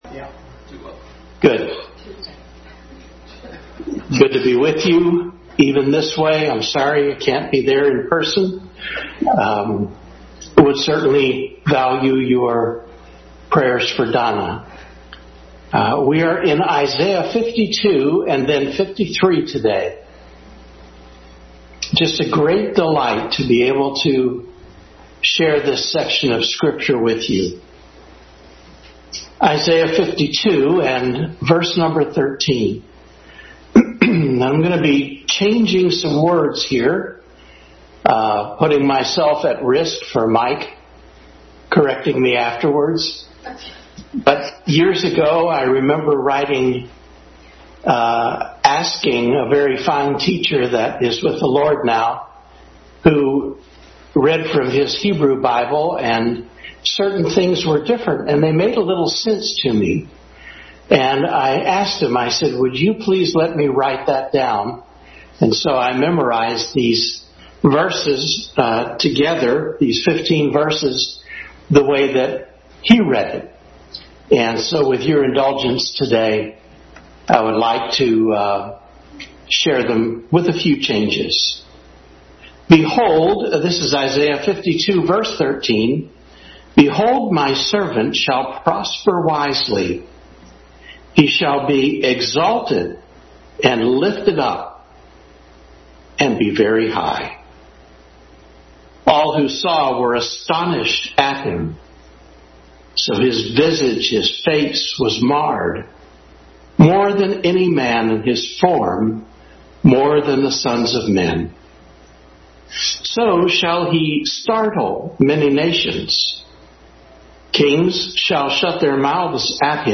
Adult Sunday School Class continued study of Christ in Isaiah.